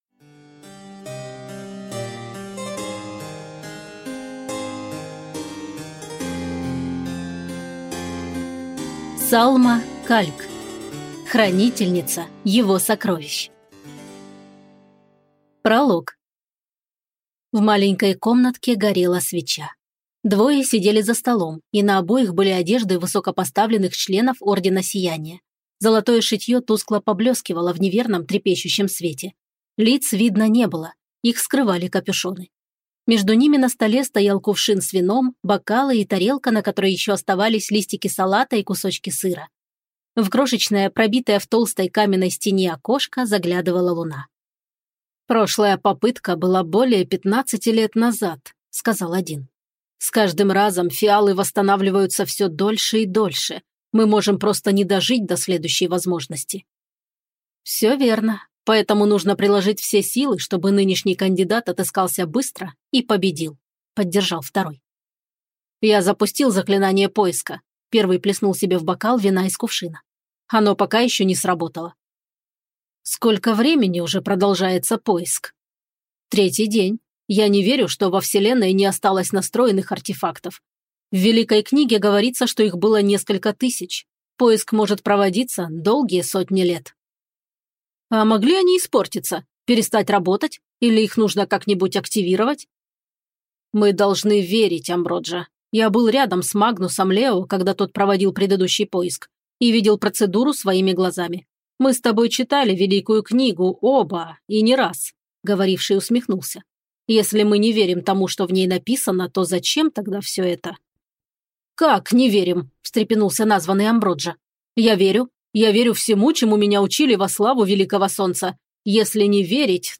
Аудиокнига Хранительница его сокровищ | Библиотека аудиокниг